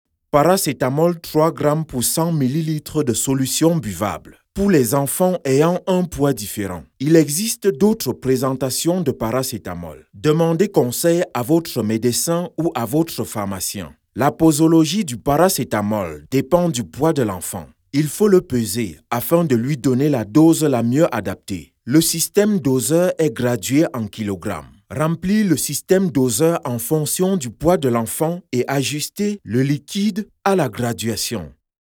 0413Upbeat_medical_explainer.mp3